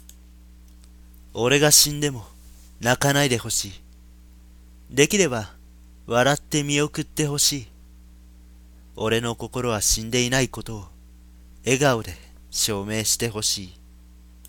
お昼の台本覚えの合間に、少し息抜きがてら録音してみました。